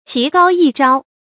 棋高一着 qí gāo yī zhāo
棋高一着发音